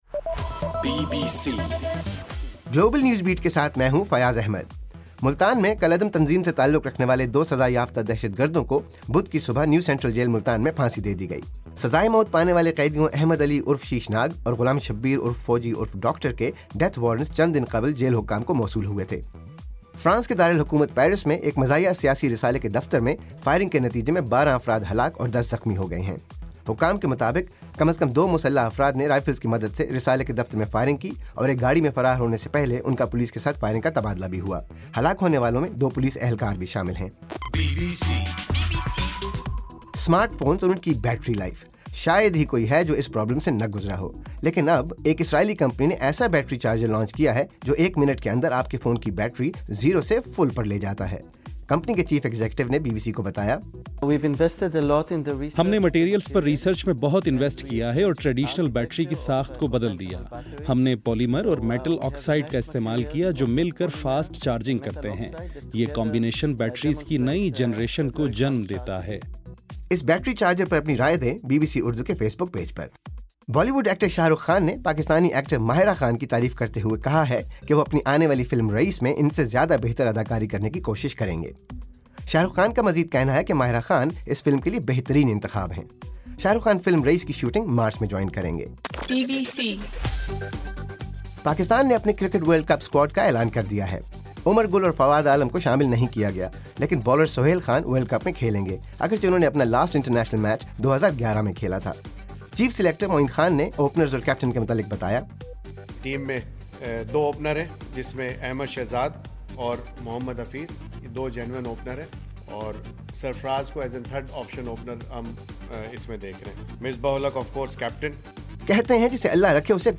جنوری 7: رات 8 بجے کا گلوبل نیوز بیٹ بُلیٹن